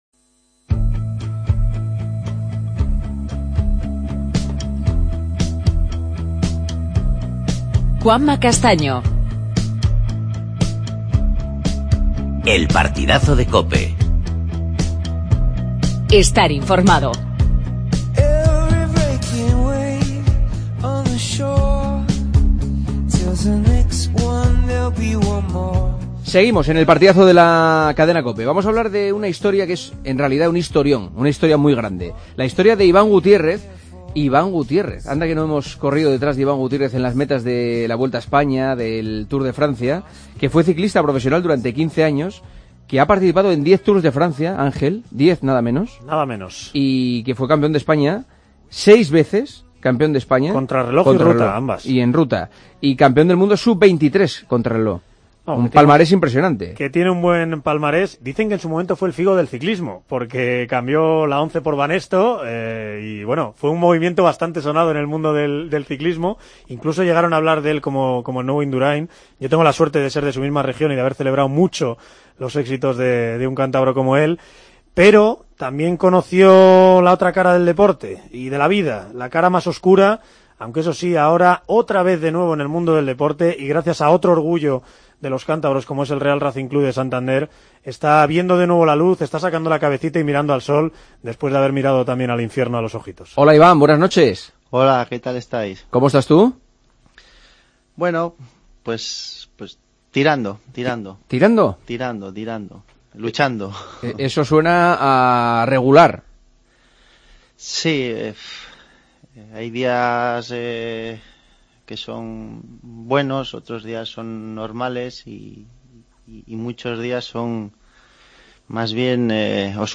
AUDIO: Entrevista al ex ciclista Iván Gutiérrez, que nos cuenta el drama que vive en su via.